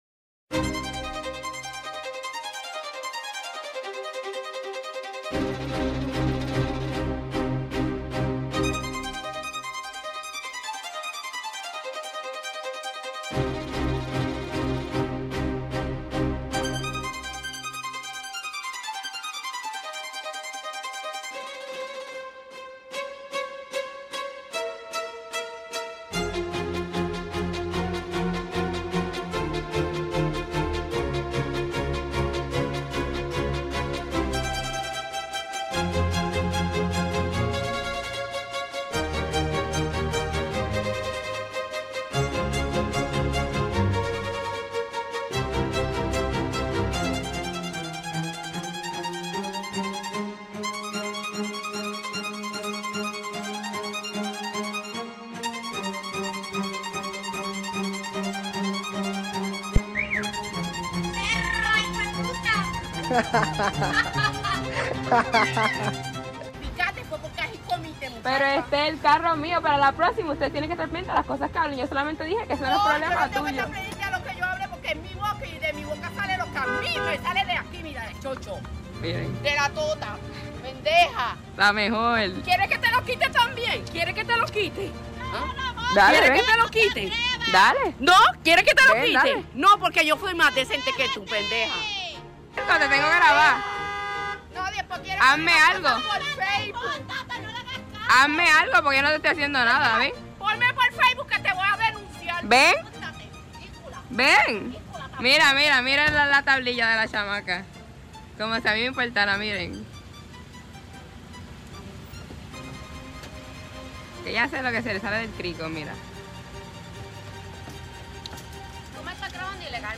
Llegó el lunes, En Vivo, y nosotros estamos más pompeaos que la Selección Nacional. Luego de 20 años, por fin, volvemos a unas Olimpiadas pero parece ser que hay gente que se dio cuenta que la mayoría de los jugadores no son nativos.